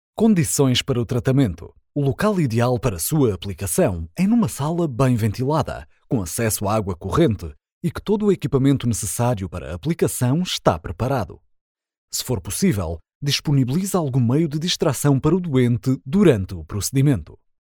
Portuguese voice over
locutor de Portugal